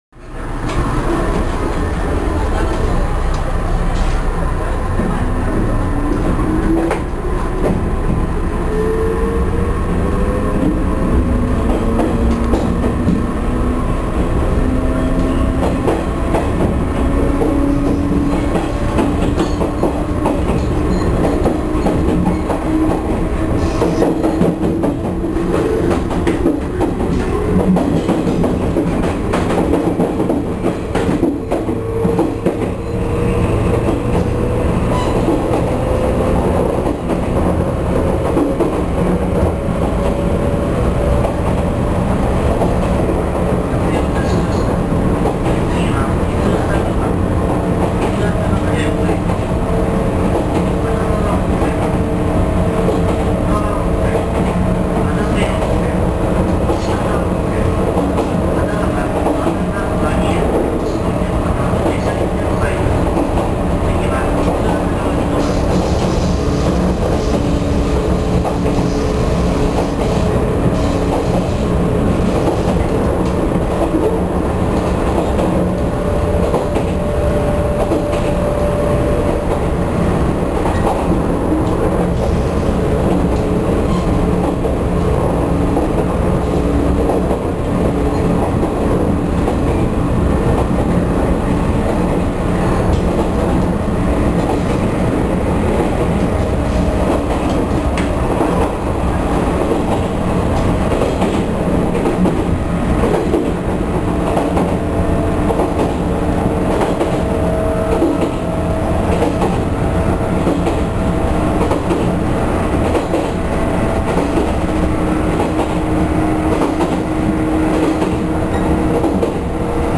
１８５系「踊り子」走行音２（wav形式・約３ＭＢ）※２
１８５系「踊り子」走行音２（mp3形式・約１７ＭＢ）※２・こちらの方が音質良好です。
※２：モハ１８４−６号で収録。２００９年９月４日収録。伊豆急行線伊豆高原−伊豆熱川間。９分。禁転載。